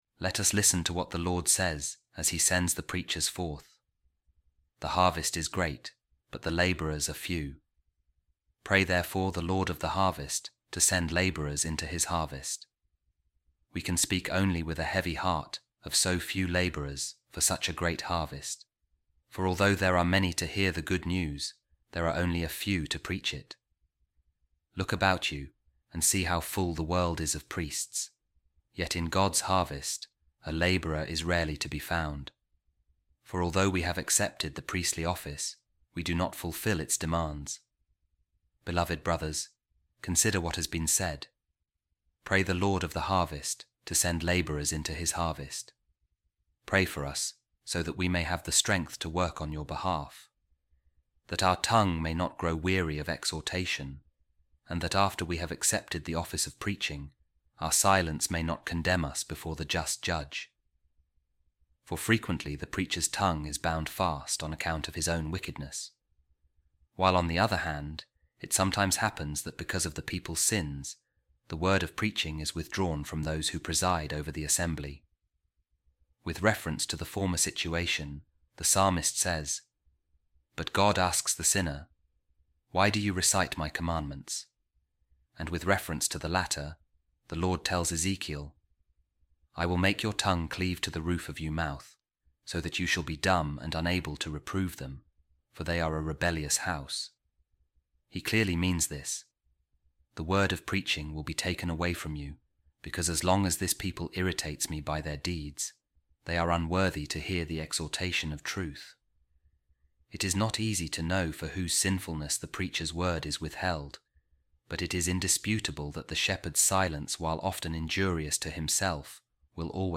A Reading From The Homilies Of Pope Saint Gregory The Great On The Gospels | Our Ministry Is Action